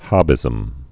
(hŏbĭzəm)